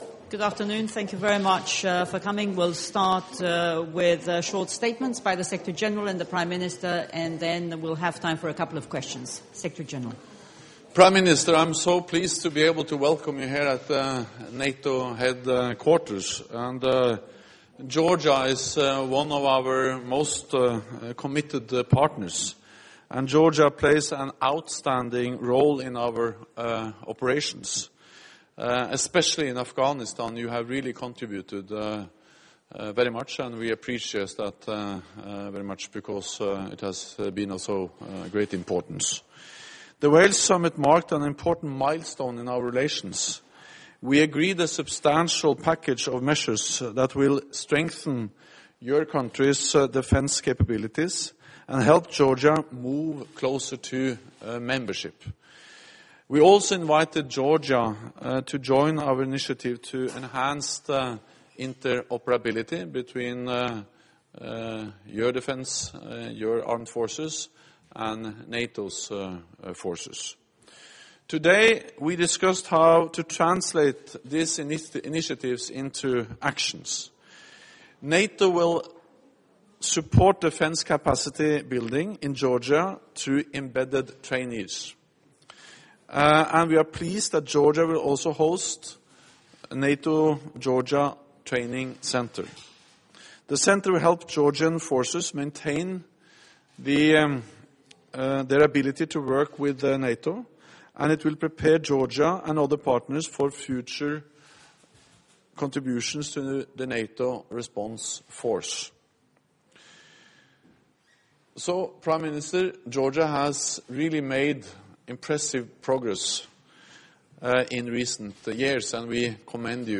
Joint press point with NATO Secretary General Jens Stoltenberg and the Prime Minister of Georgia, Irakli Garibashvili